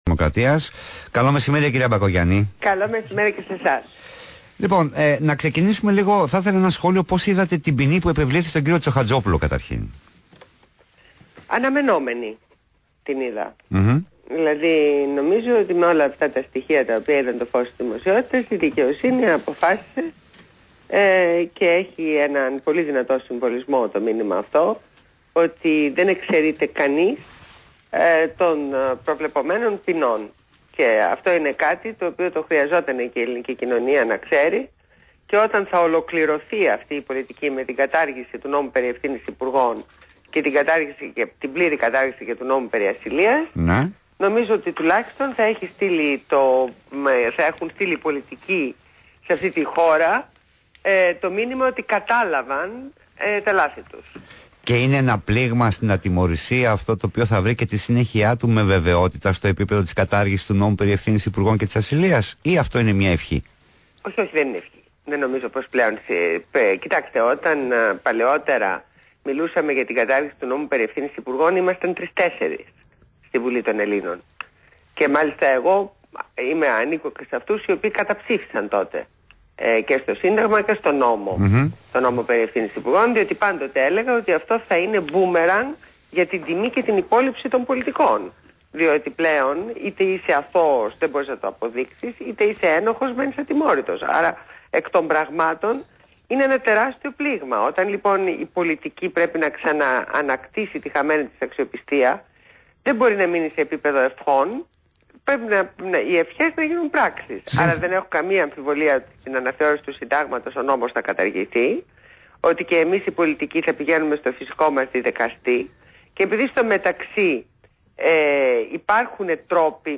Συνέντευξη στο ραδιόφωνο ΒΗΜΑ FM 99,5